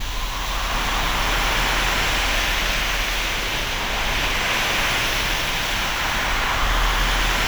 STORMY SEA.wav